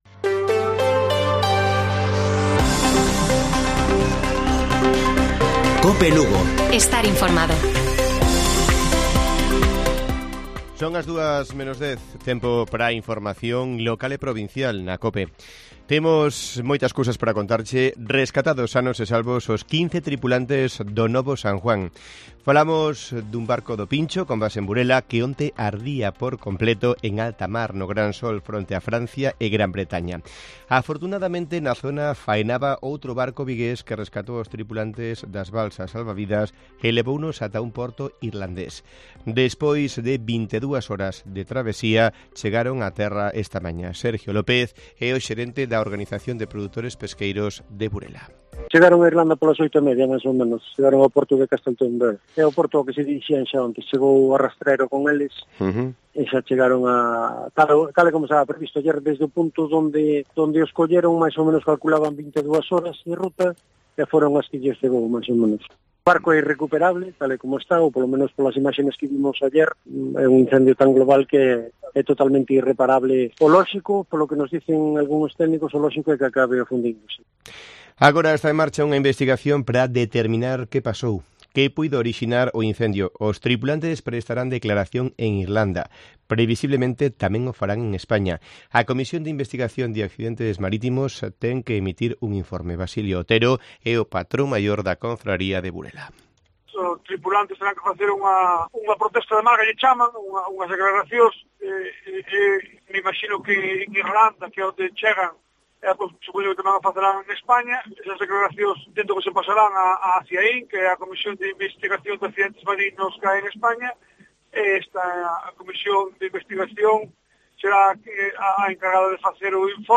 Informativo Mediodía de Cope Lugo. 03 DE JULIO. 13:50 horas